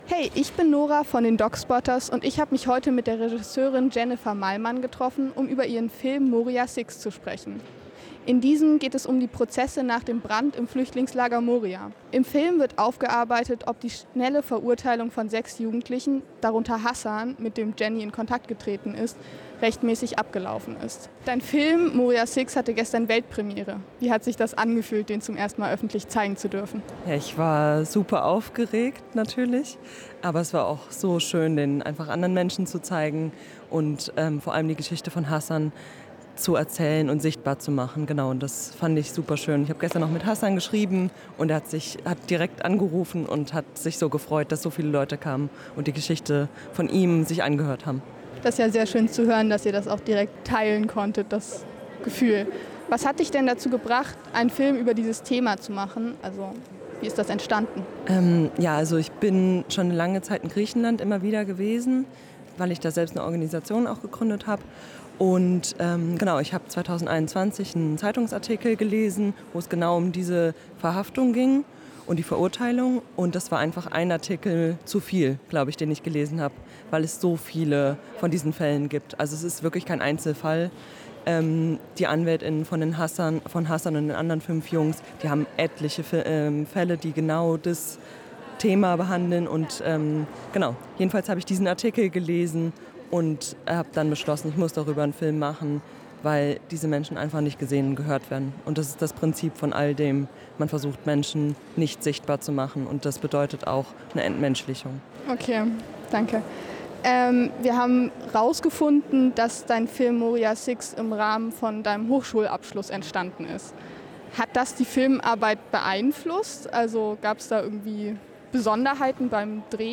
Von DOK Spotters 2024Audio, Interview